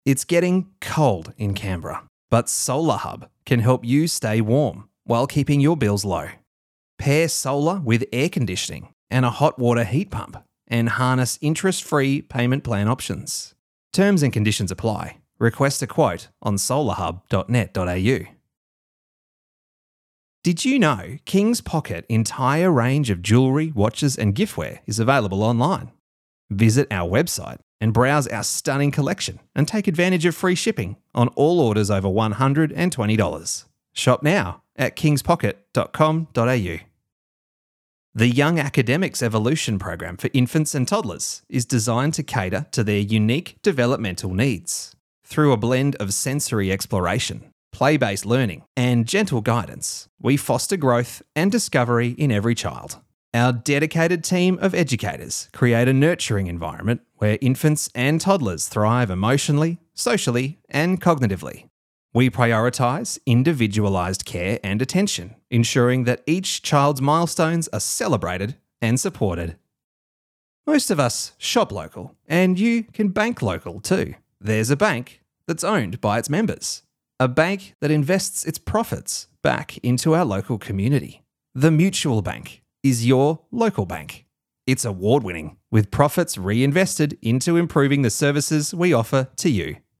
Male
Relatable, conversational male Australian voice.
Natural, friendly, warm, approachable, affable, every-man style Australian voice.
Natural Speak
Soft Sell Style